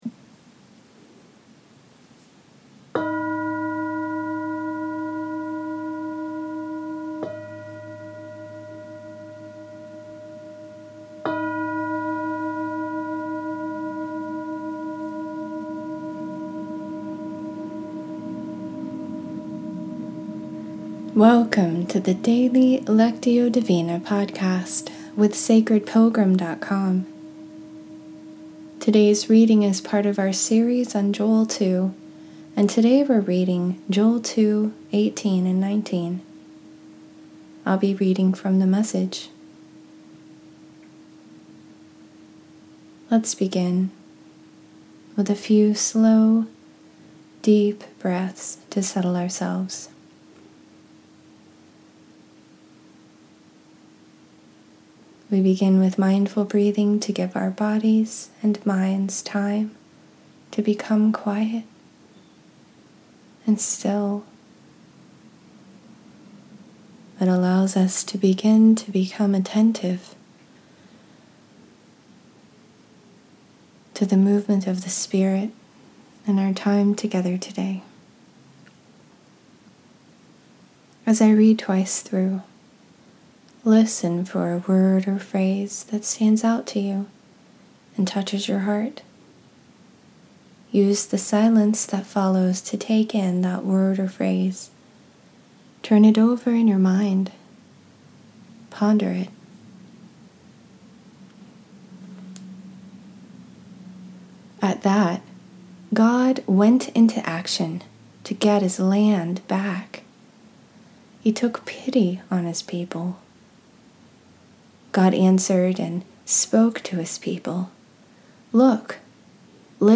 In this episode we’re reading Joel 2:18-19.